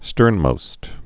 (stûrnmōst)